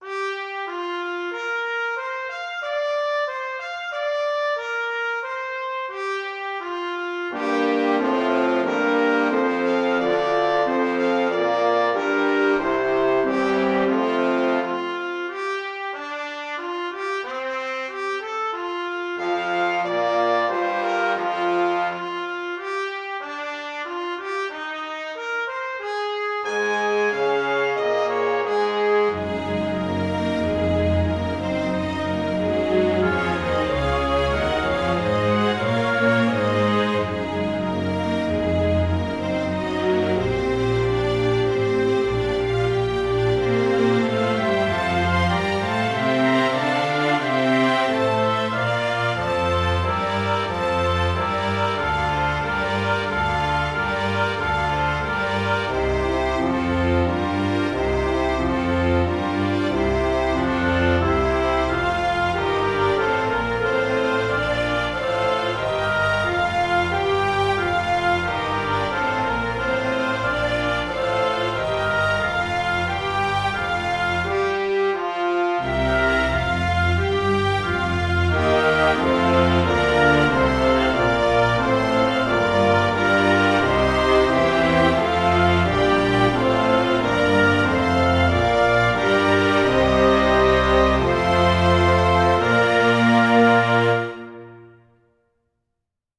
Finally there's an excerpt from Promenade from Pictures at an Exhibition by Mussorgsky, arranged for an orchestra.
No processing of any kind, all the reverb is present in the samples.